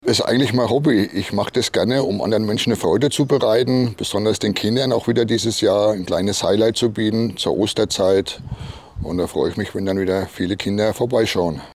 Interview: Das Osterhaus in Mellrichstadt - PRIMATON